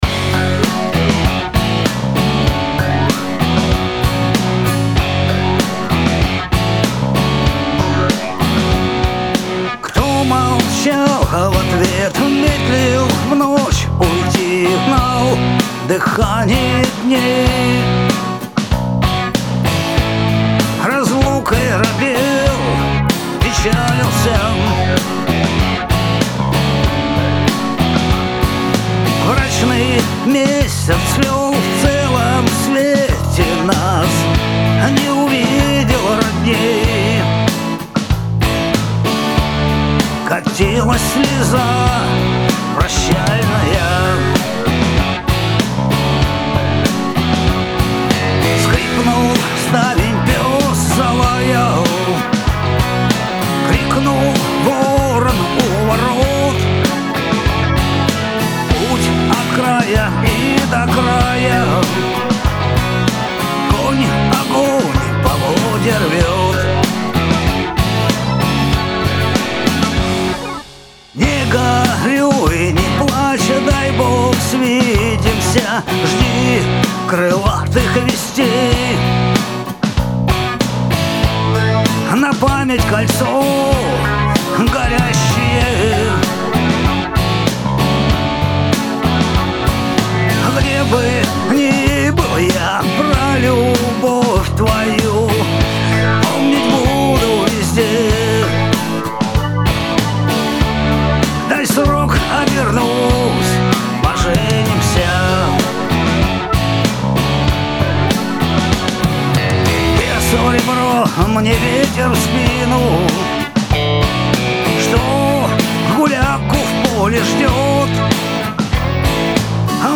Замечательный звук, гитары на высоте!